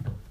wood_walk.ogg